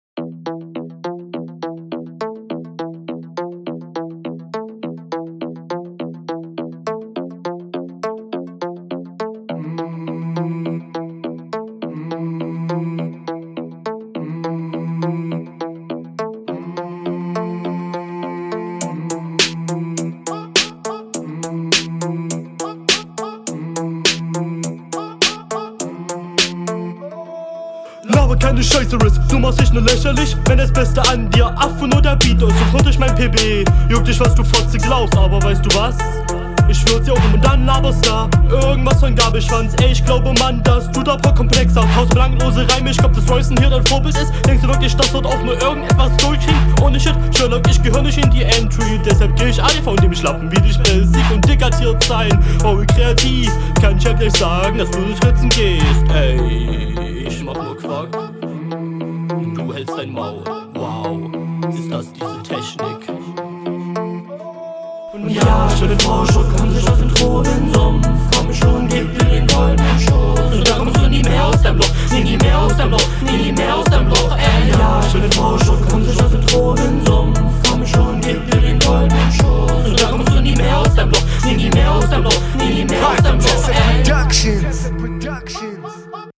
Flow: Etwas seichter vom Gesamtpaket.